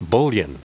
Prononciation du mot bullion en anglais (fichier audio)
Prononciation du mot : bullion